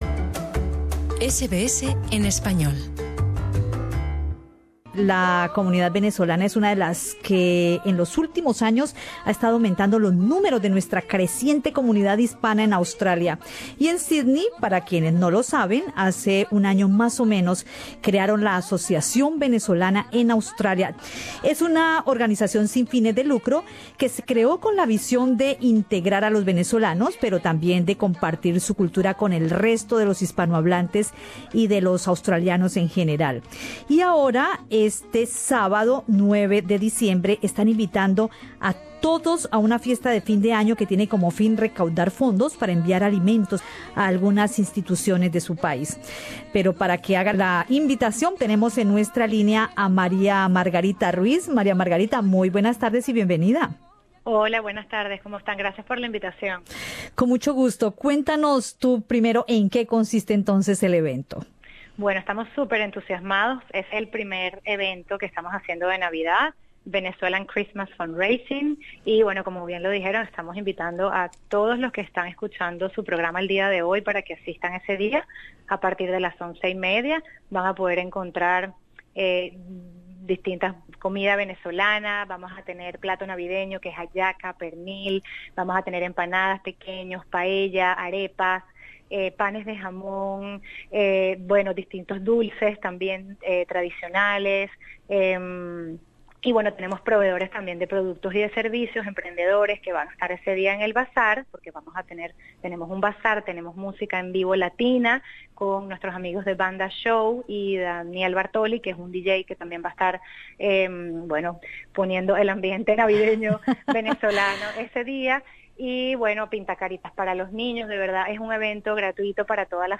En Radio SBS conversamos